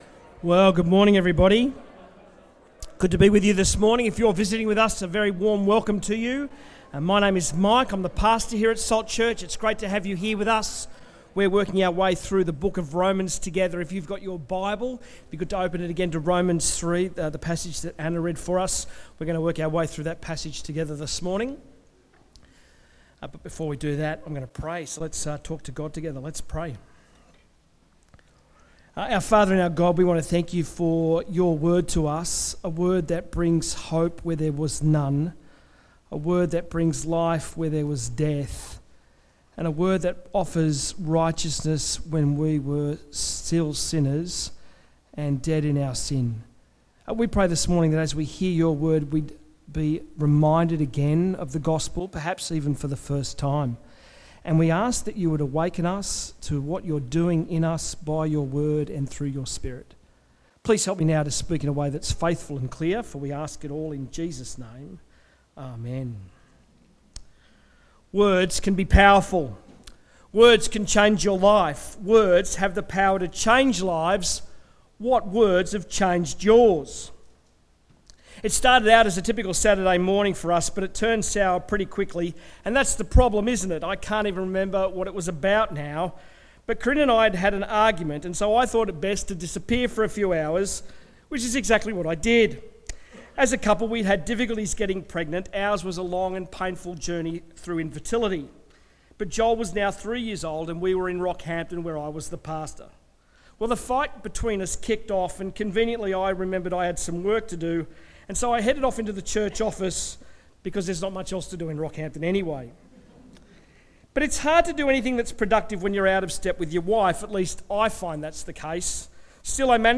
Sermons
Bible talk on Romans 3 for the Life of Faith Series